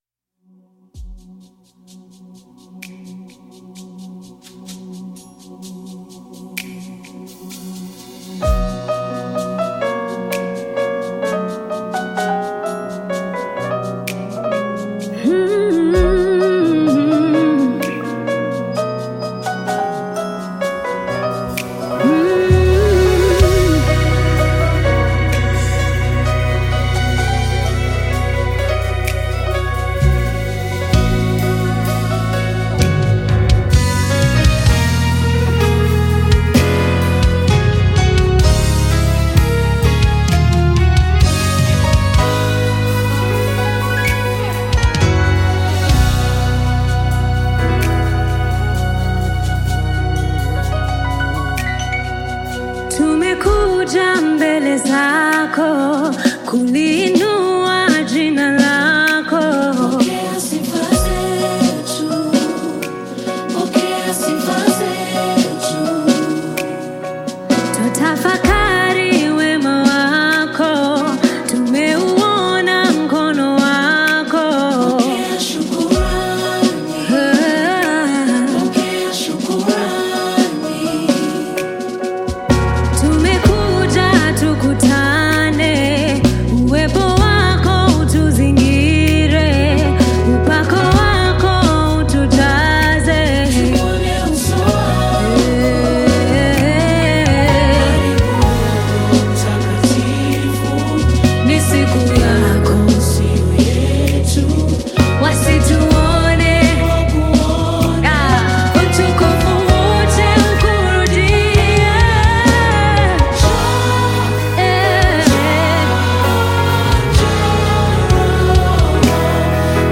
Gospel music track
Kenyan gospel
Gospel song